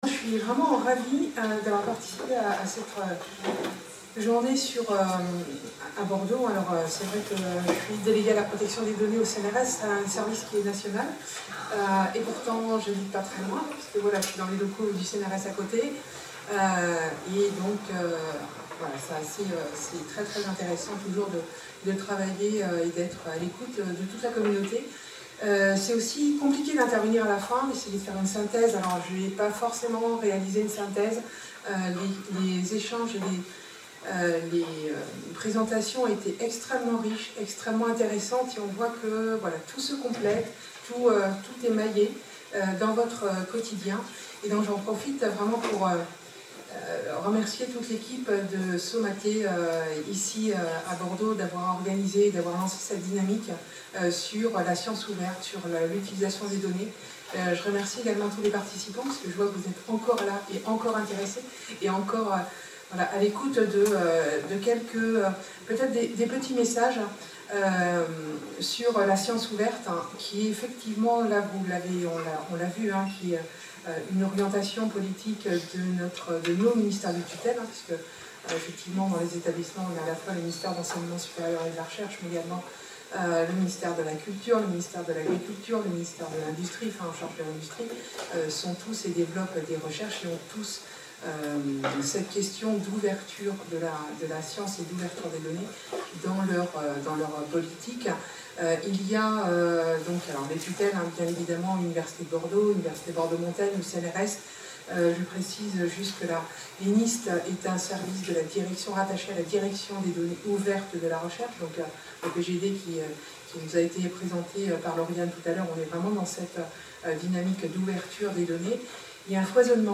Tous formés à la Science ouverte - Discours de clôture